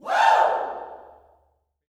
WOO  05.wav